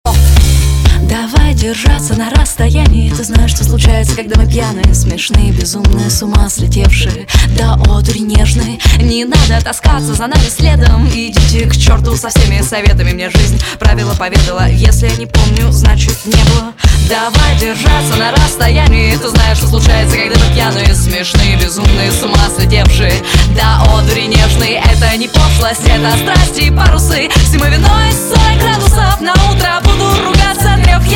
• Качество: 192, Stereo
поп-рок